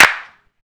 • Clap Single Shot F# Key 26.wav
Royality free clap sample - kick tuned to the F# note. Loudest frequency: 2379Hz
clap-single-shot-f-sharp-key-26-h0o.wav